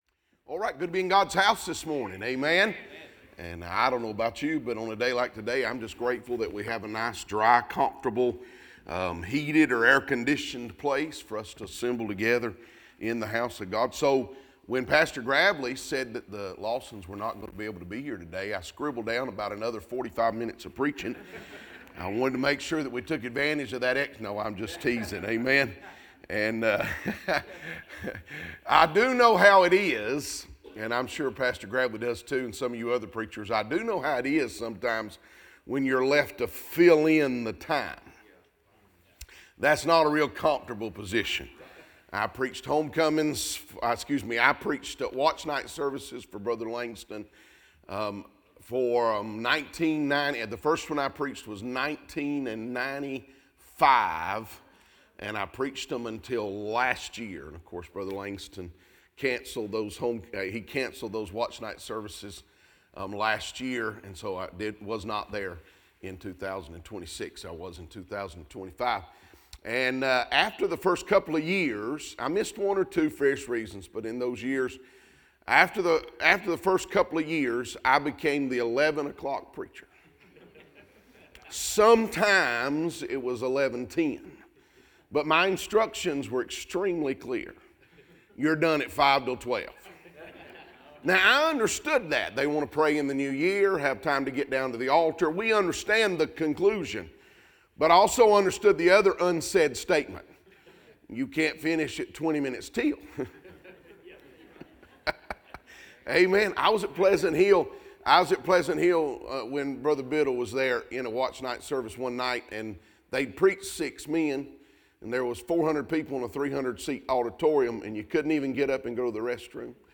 Listen to the most recent sermons from Bible Baptist Church